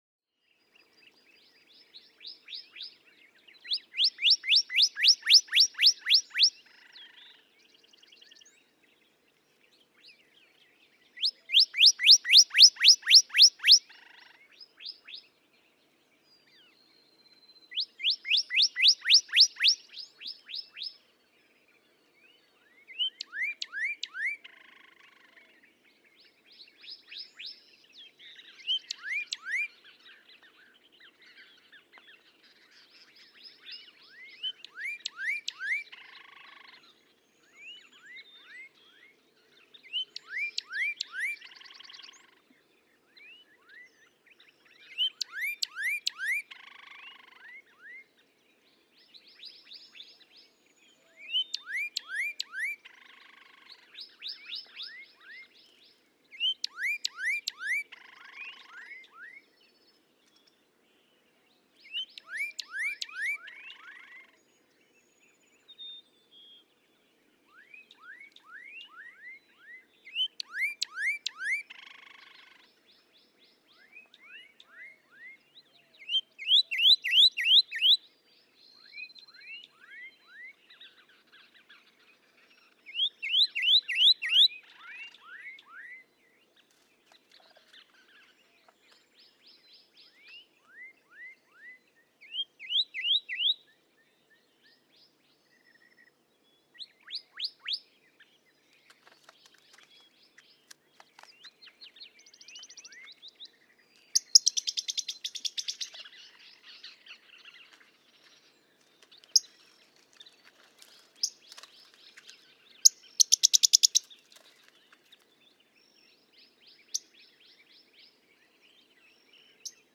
Pyrrhuloxia: This close relative of northern cardinals also engages in song matching; you can hear good matching during the first 20 seconds, after which both males switch to another song.
Chaparral Wildlife Management Area, Artesia, Texas.
226_Pyrrhuloxia.mp3